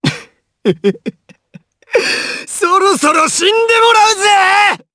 Kibera-Vox_Skill6_jp_b.wav